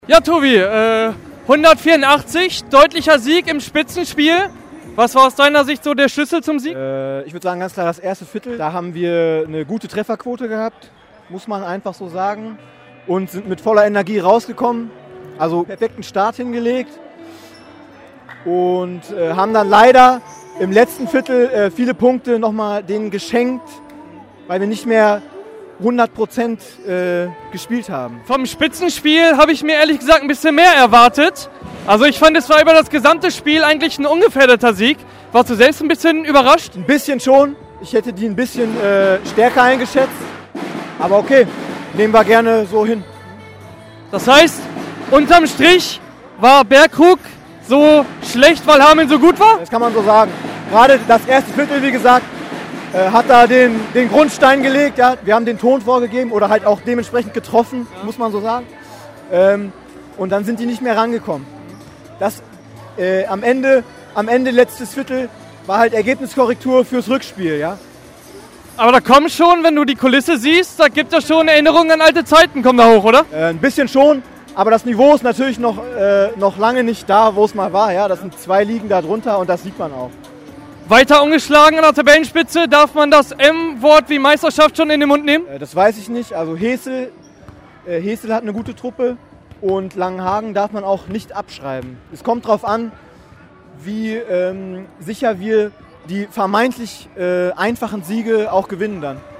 Reportage vom Spiel VfL Hameln vs. TV Bergkrug